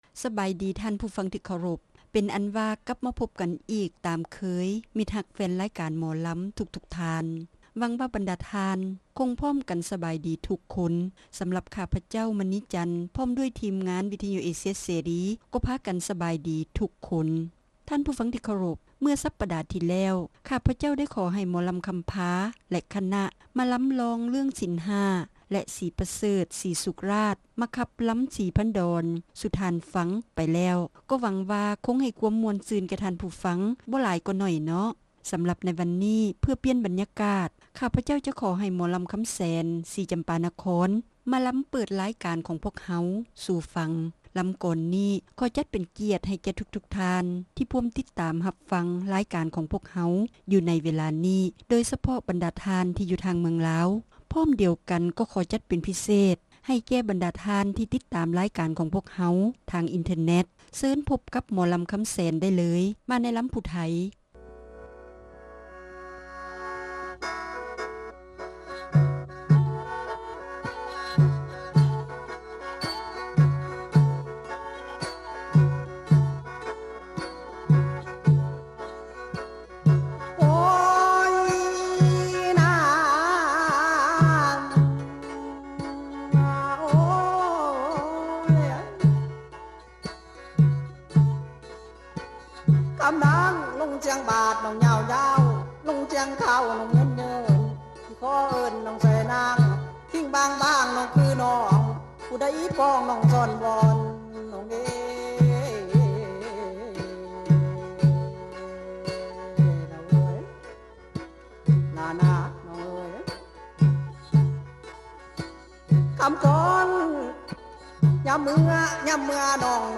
ຣາຍການໜໍລຳ ປະຈຳສັປະດາ ວັນທີ 3 ເດືອນ ສິງຫາ ປີ 2007